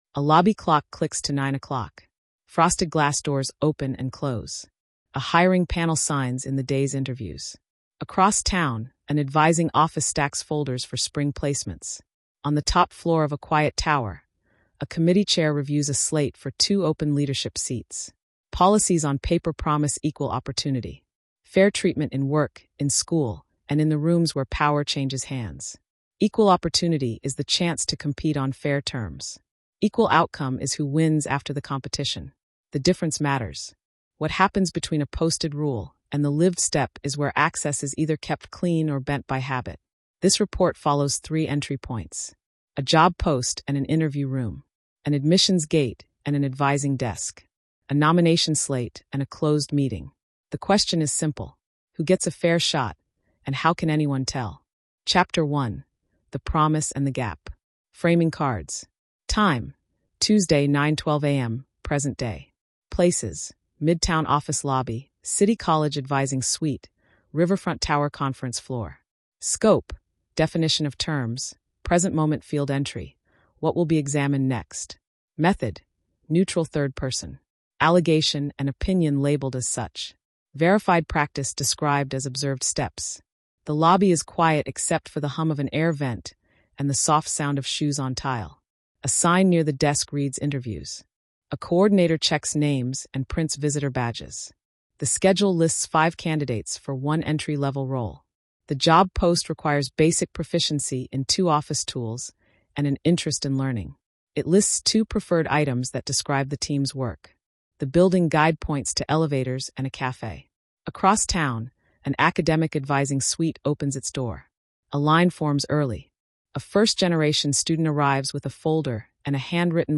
This documentary-style report traces three doors—an interview panel, a practicum gate, and a leadership slate—to show how access rises or falls on design, timing, and records.